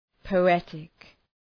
Προφορά
{pəʋ’etık}